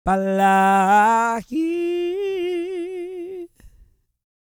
E-CROON 208.wav